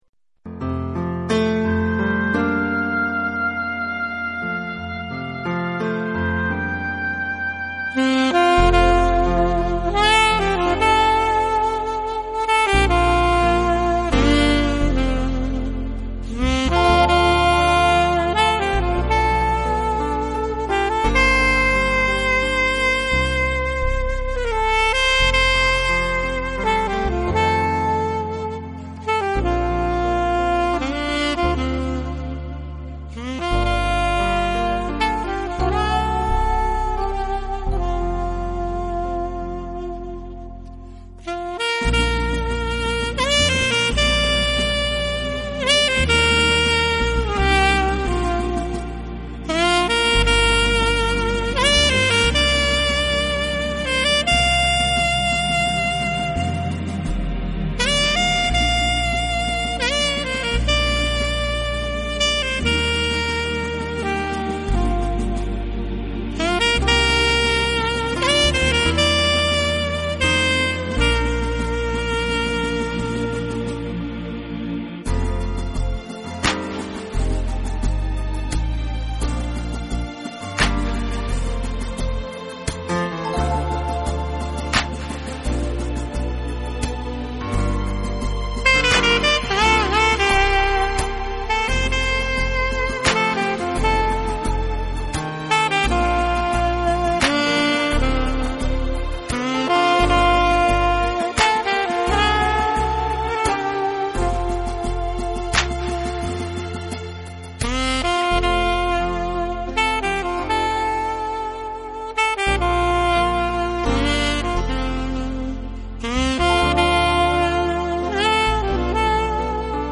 Esses dedinhos nos os gravamos aos microfones da Rádio Você, em Americana, interior do Estado de São Paulo.